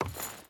Wood Chain Walk 2.ogg